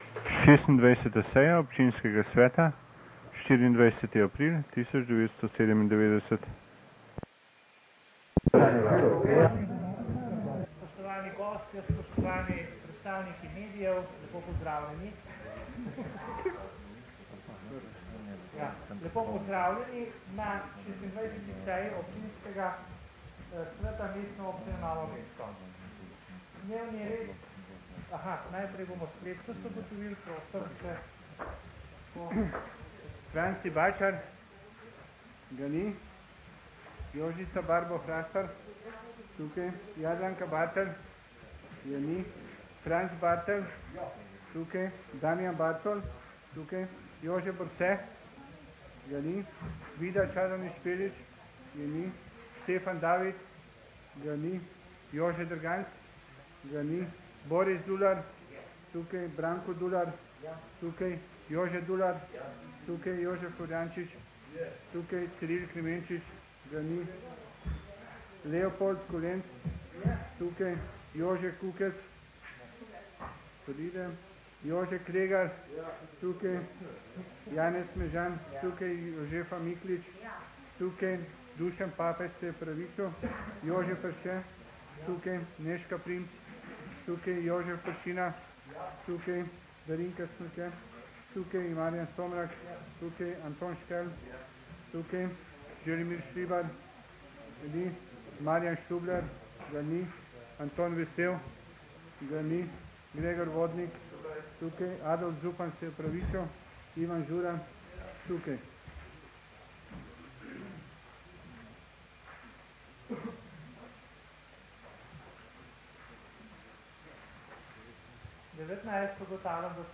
26. seja Občinskega sveta Mestne občine Novo mesto - Seje - Občinski svet - Mestna občina